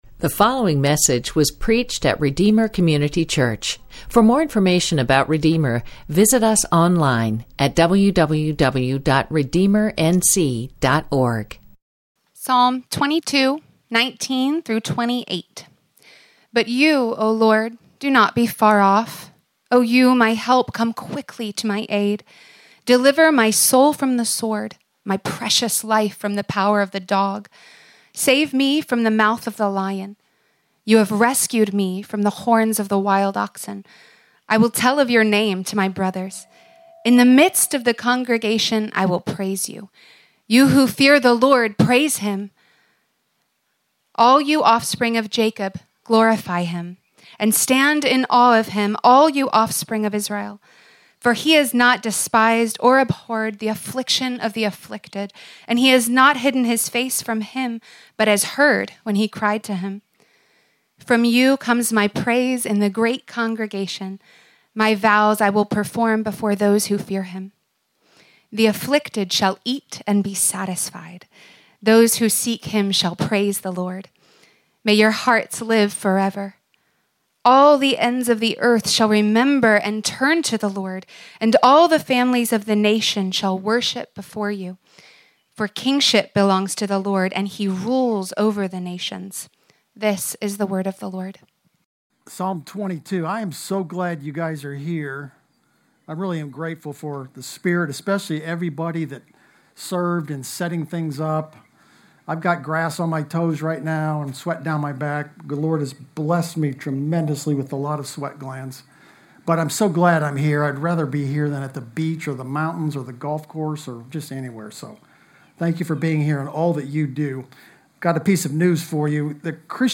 A sermon from the series "Son of the Most High."